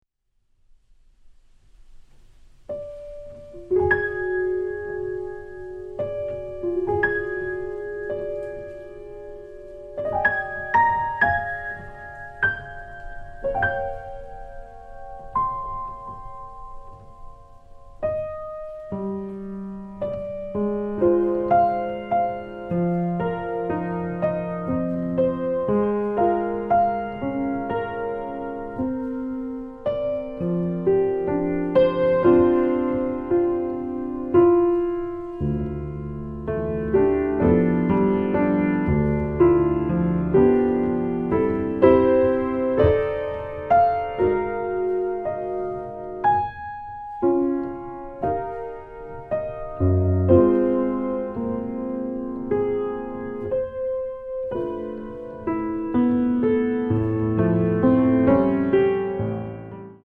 空間を意識させる旋律と響きの妙が強く印象に残る傑作です！